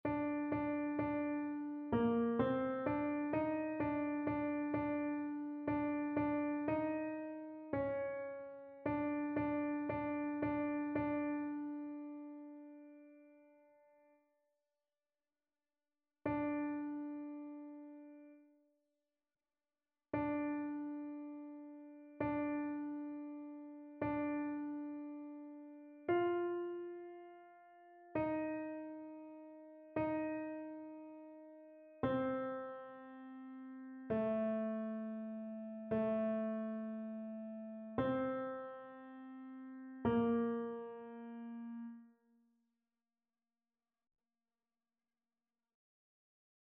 annee-b-temps-du-careme-4e-dimanche-psaume-136-alto.mp3